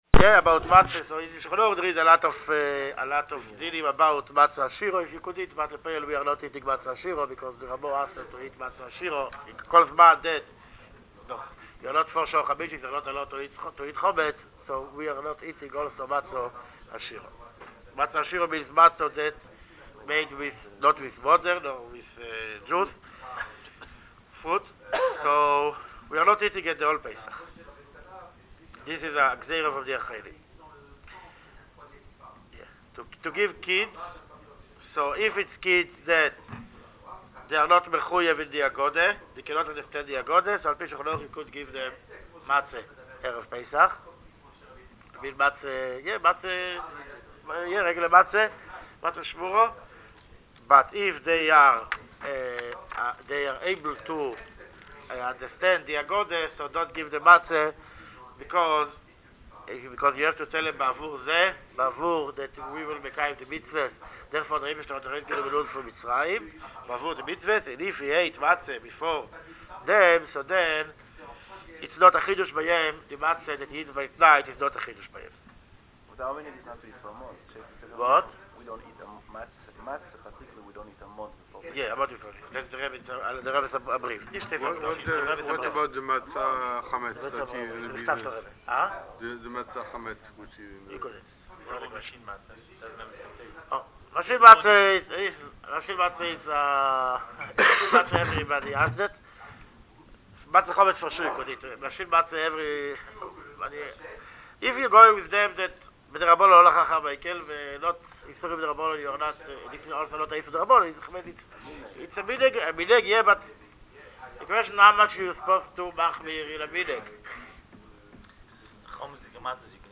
כמנהג 'שלושים יום לפני החג', נמסר השבוע בבית-כנסת אנ"ש המרכזי במונטריאול שיעור מיוחד בענייני חג הפסח הקרב.